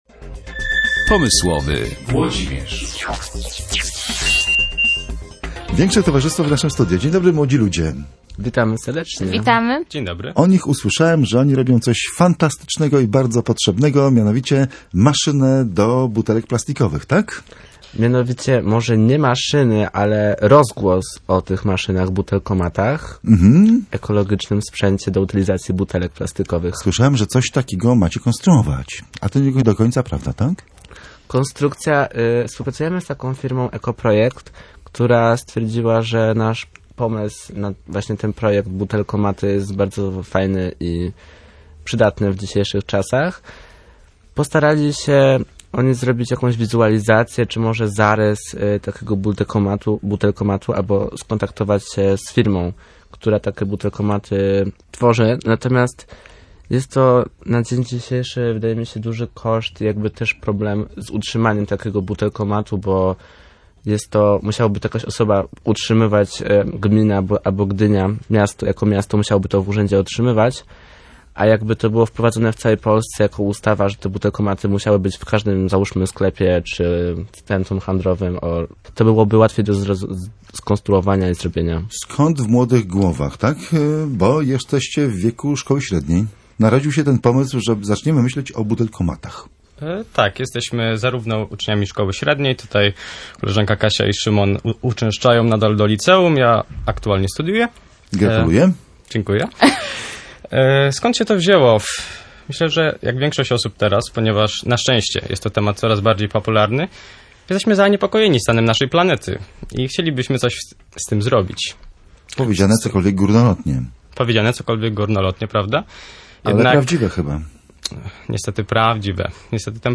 Posłuchaj rozmowy z grupą Bottle Spirit Polska.